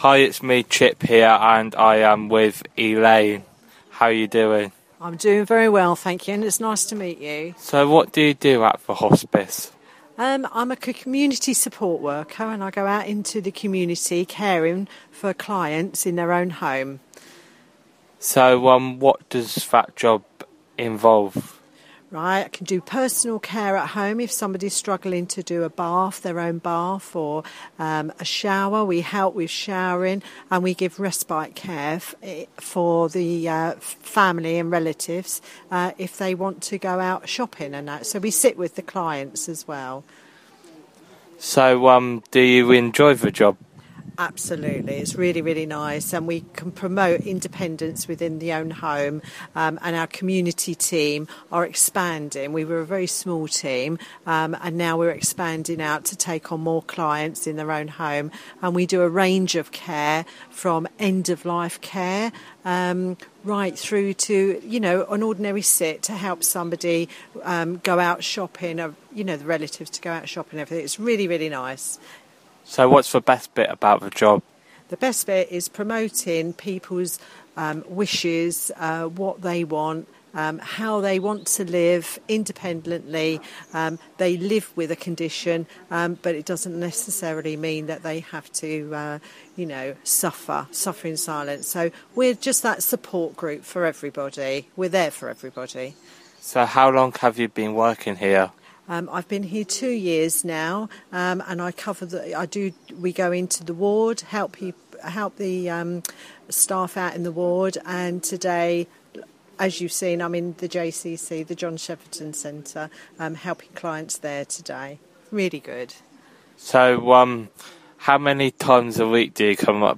Interview.